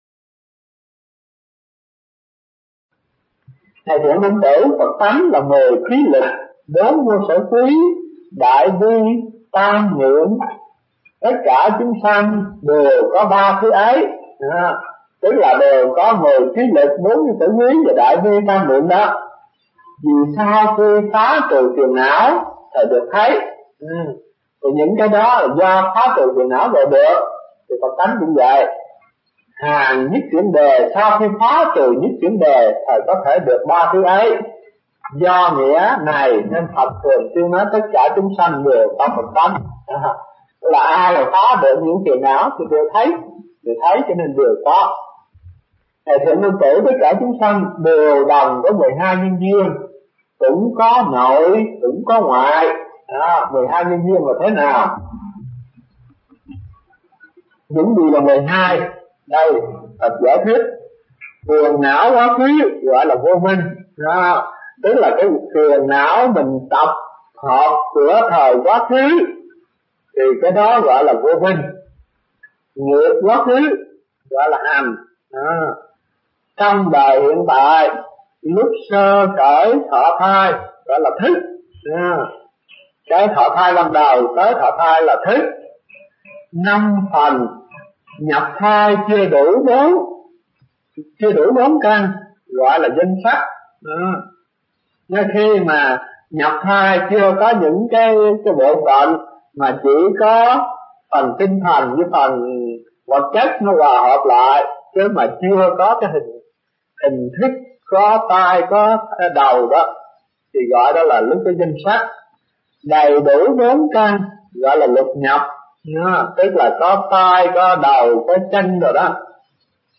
Mp3 Bài Pháp Âm Kinh Đại Bát Niết Bàn 22 – Sư Tử Hống Bồ Tát Phần 2 – Hòa Thượng Thích Thanh Từ